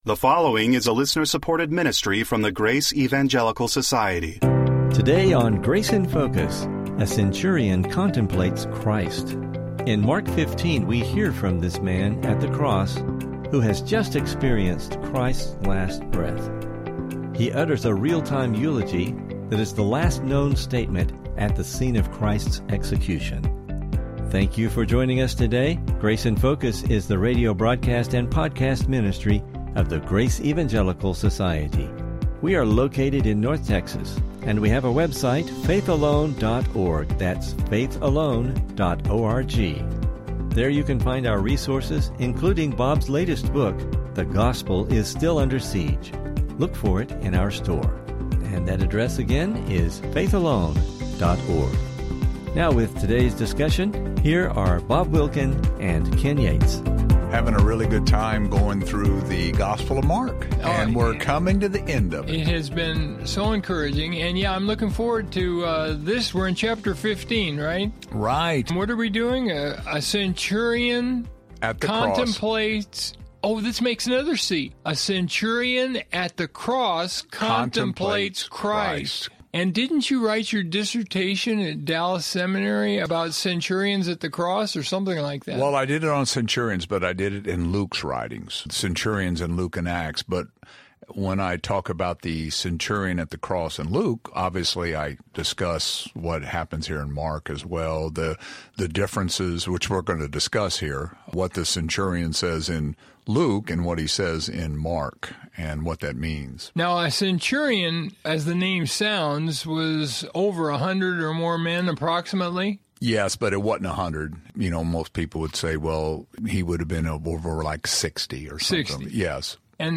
Please listen for an interesting discussion and lessons related to this event.